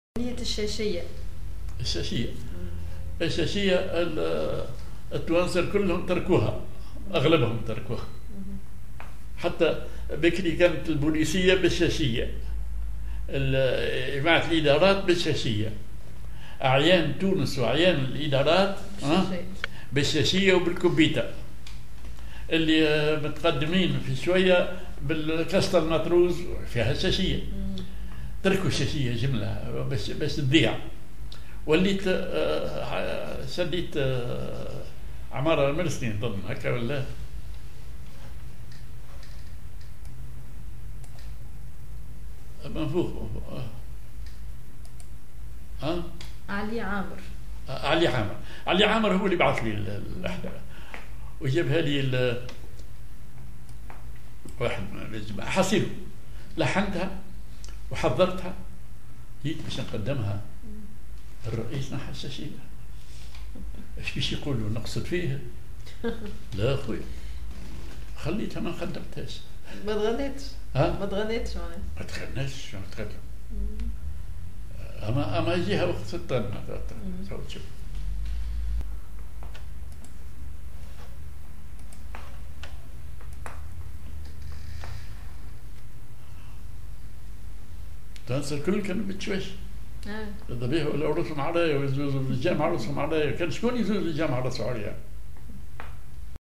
Maqam ar محير عراق
genre أغنية